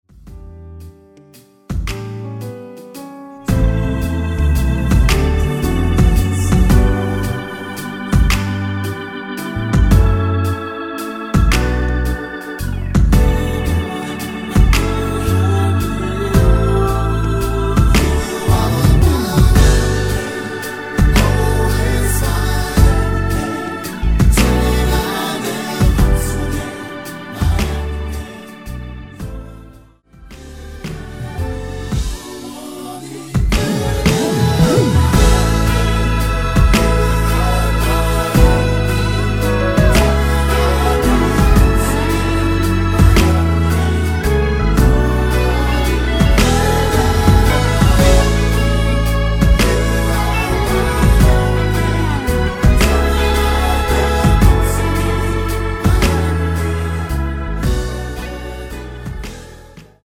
(-1) 내린코러스 포함된 MR 입니다.(미리듣기 참조)
◈ 곡명 옆 (-1)은 반음 내림, (+1)은 반음 올림 입니다.